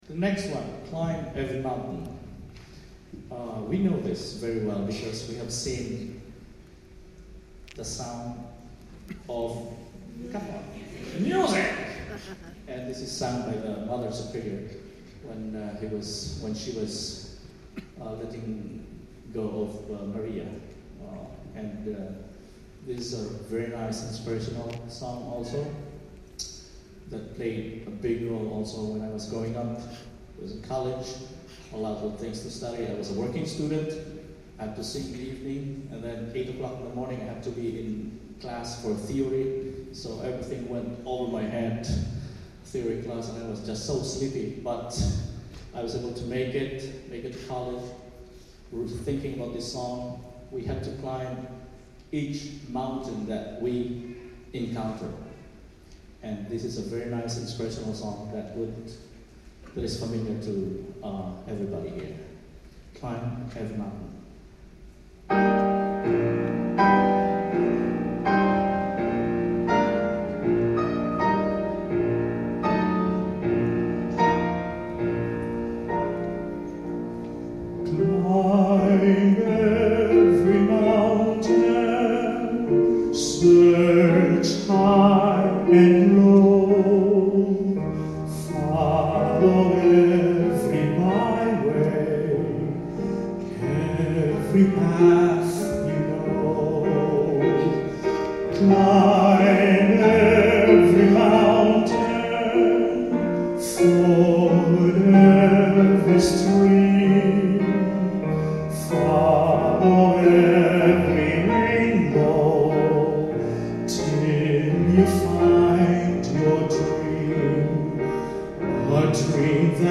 I took the opportunity to test out the in-ear binaurals I got from The Sound Professionals and borrowed an old MiniDisc player.
These songs are best heard on headphones to hear the direction of chatter, laughter and so on.